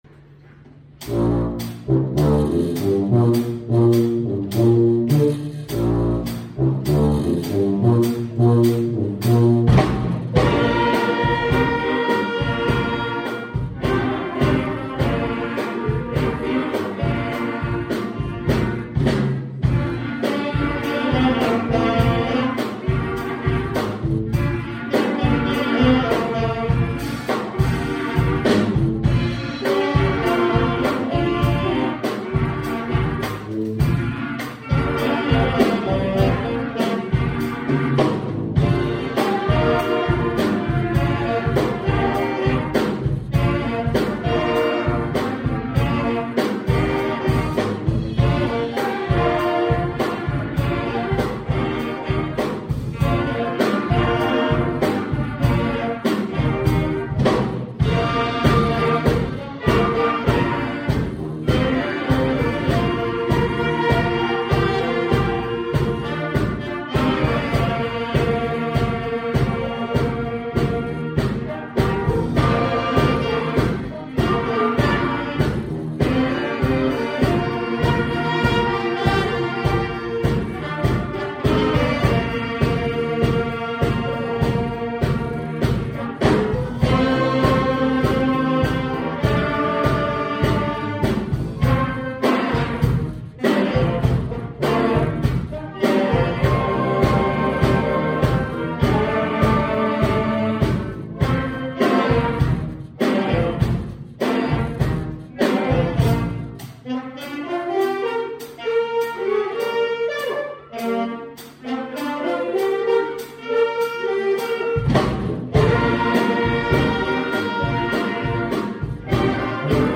Domino | Larch Wind and Brass